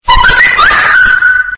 Categoria: Toques